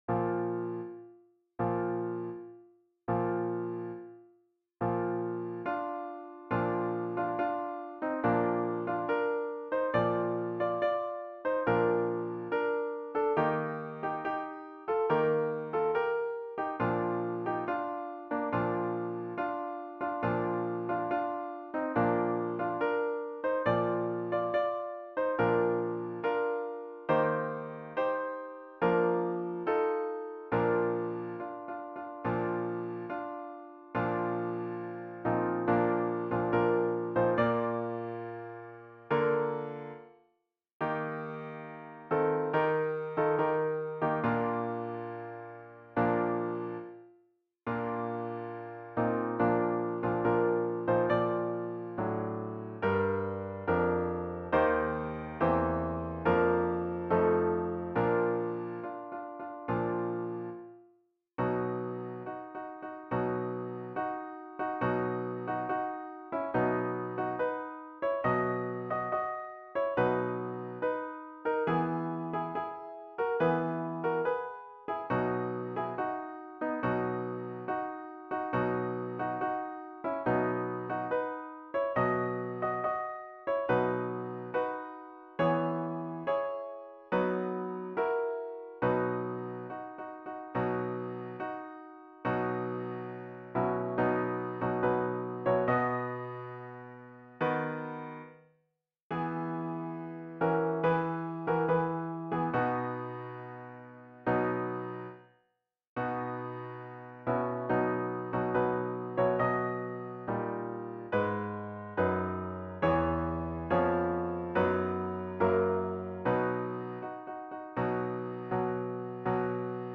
A capella arrangements for mixed chorus.
You can almost hear the pipes and drums.